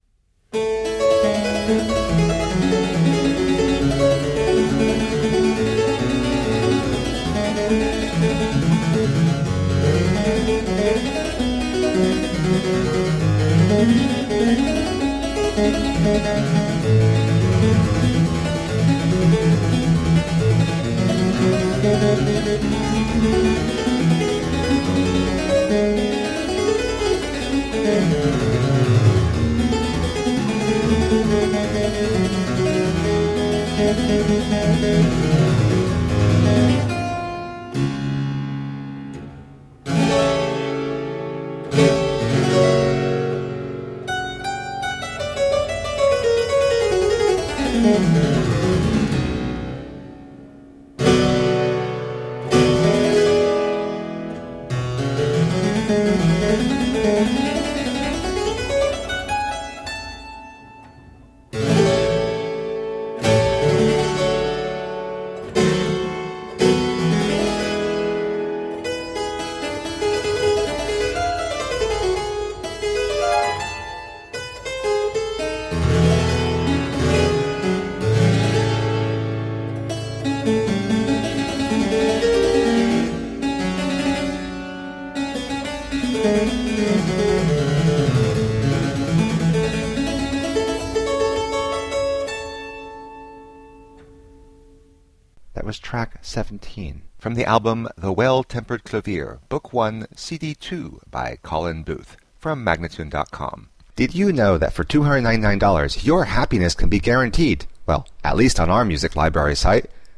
Solo harpsichord music
Tagged as: Classical, Baroque, Instrumental Classical
Harpsichord